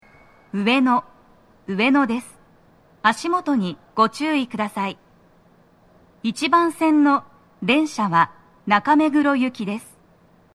スピーカー種類 TOA天井型
足元注意喚起放送が付帯されています乗降が多く、フルはそこまで粘らず録れます
女声